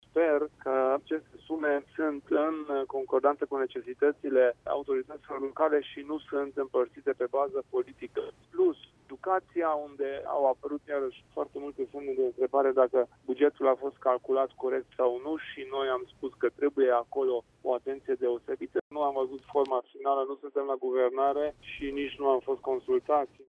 Şi UDMR îşi exprimă unele îndoieli, după cum explică preşedintele Uniunii, Kelemen Hunor :